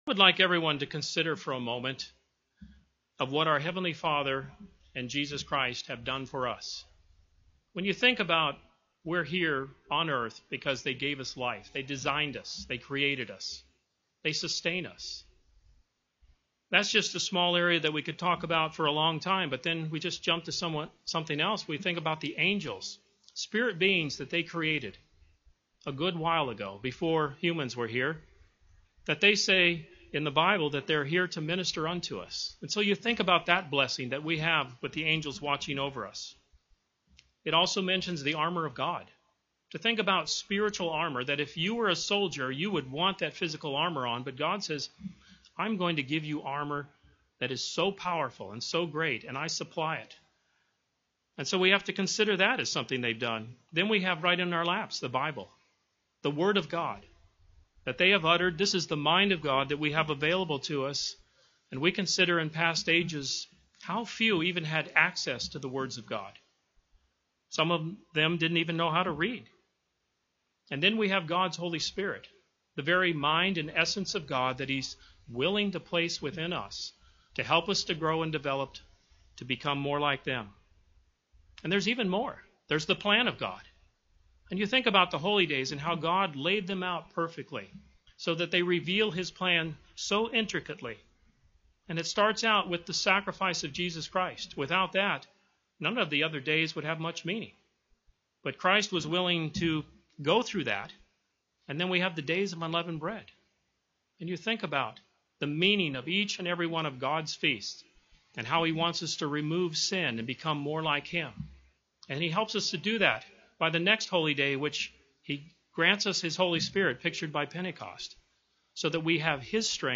Given in Orlando, FL
UCG Sermon Studying the bible?